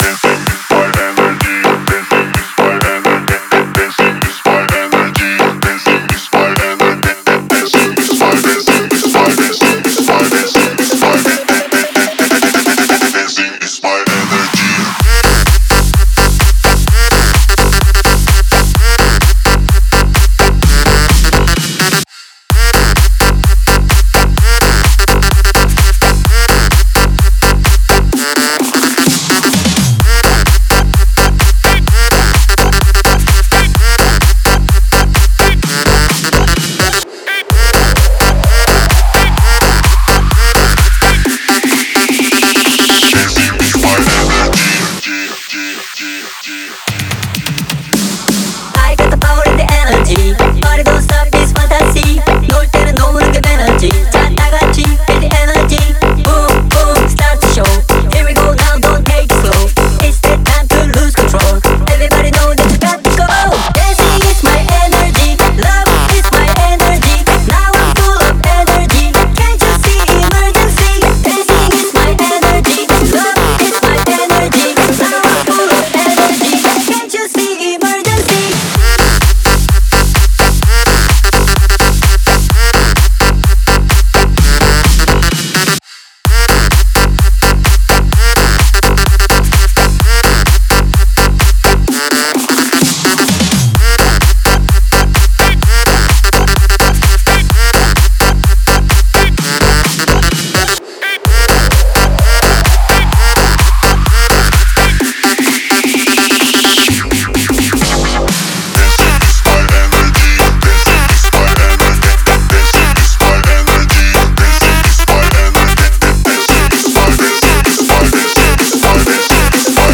это динамичный трек в жанре EDM